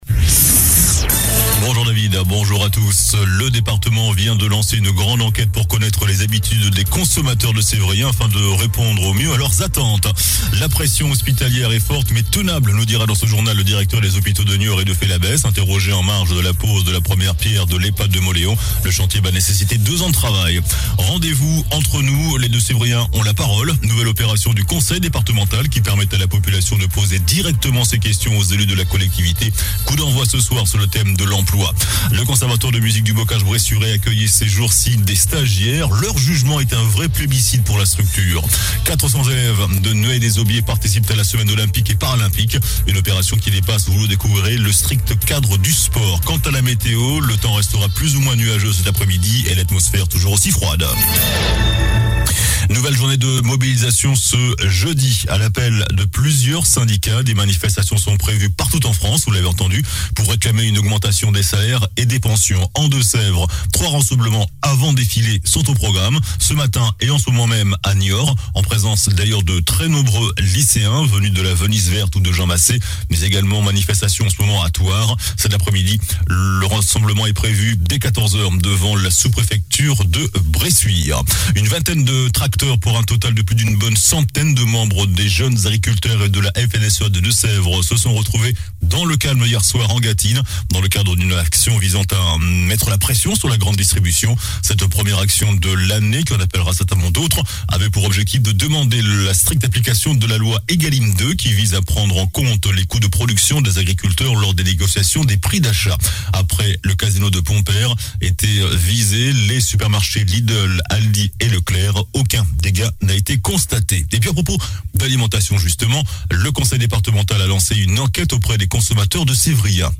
JOURNAL DU JEUDI 27 JANVIER ( MIDI )